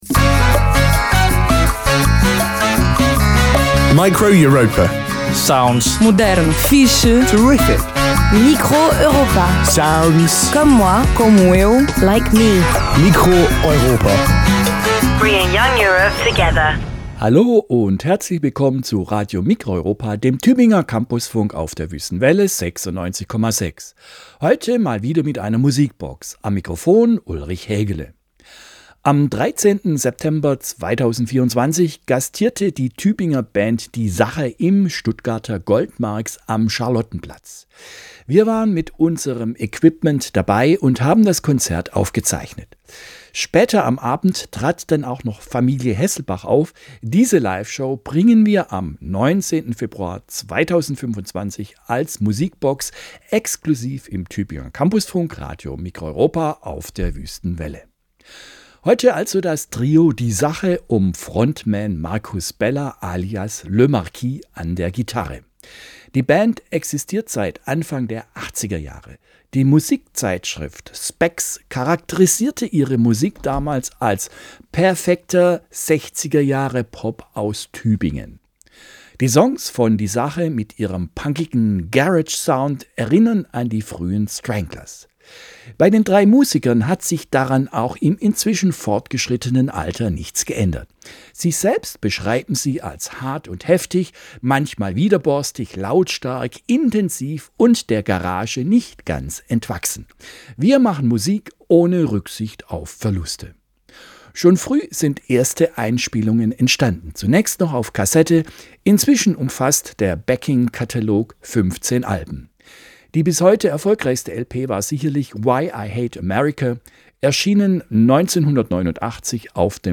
Trio
Gitarre
punkigen Garage-Sound
Form: Live-Aufzeichnung, geschnitten